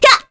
Scarlet-Vox_Attack1_kr.wav